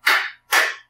lift2.mp3